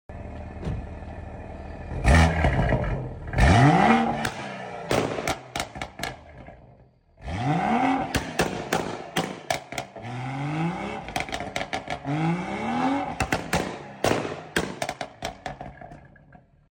Bmw 140i came in for sound effects free download
Bmw 140i came in for sound effects free download By psltuning 6 Downloads 10 months ago 16 seconds psltuning Sound Effects About Bmw 140i came in for Mp3 Sound Effect Bmw 140i came in for stage 2 , dcat , pops&bangs in sport.